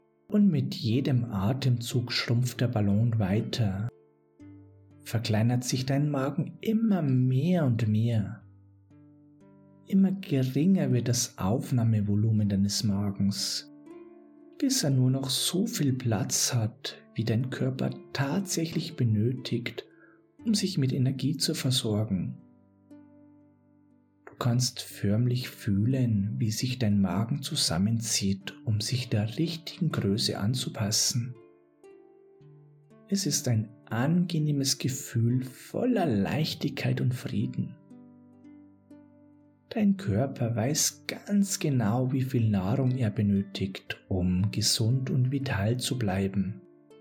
Unser erfahrener Hypnosecoach leitet Sie während der wohltuenden Einleitung in einen tiefen Entspannungszustand und führt zunächst eine kleine Blockadenlösung durch.